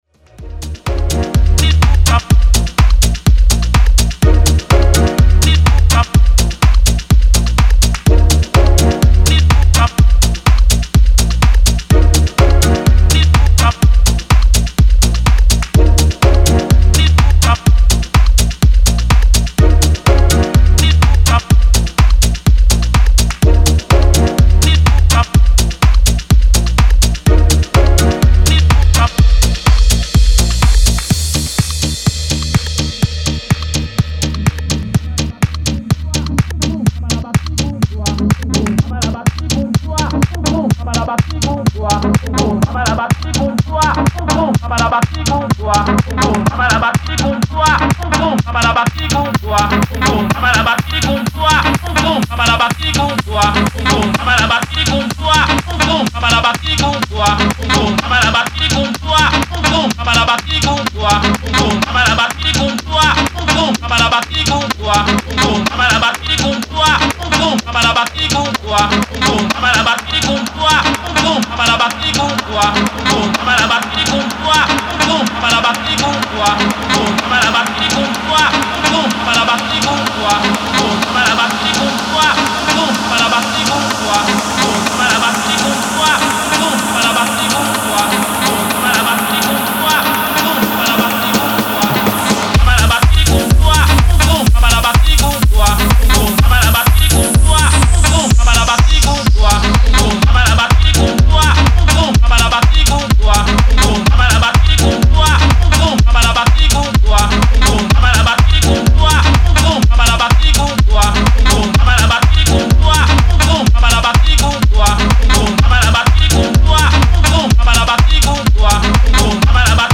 Style: House